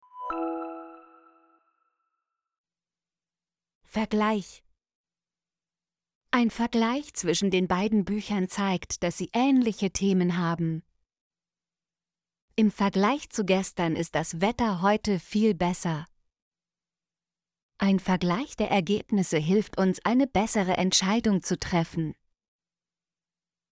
vergleich - Example Sentences & Pronunciation, German Frequency List